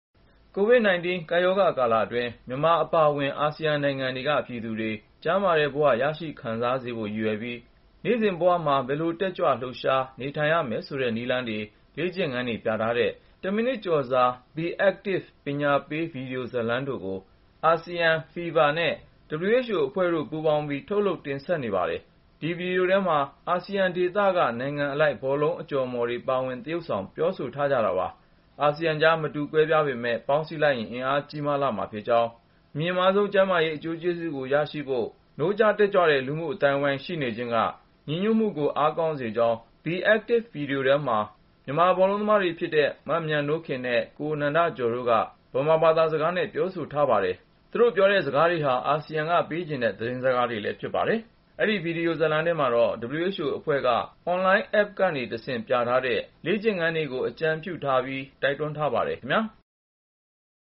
ဗွီဒီယိုထဲမှာ အာဆီယံဒေသက နိုင်ငံအလိုက် ဘောလုံးအကြော်အမော်တွေ ပါဝင် သရုပ်ဆောင် ပြောဆိုထားကြတာပါ။